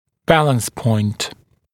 [‘bæləns pɔɪnt][‘бэлэнс пойнт]точка равновесия